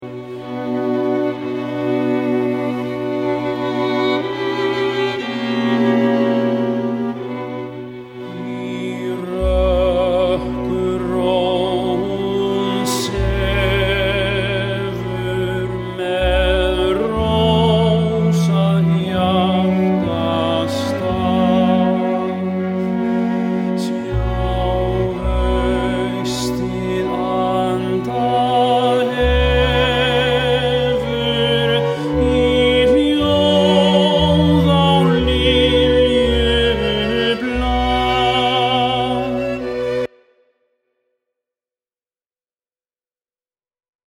einsöngslag